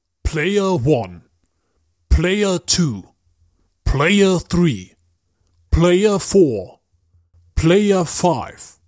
标签： 语音 语音 视频 团队死斗 语音配音 声乐 游戏 死斗 播音员 视频游戏 画外音
声道立体声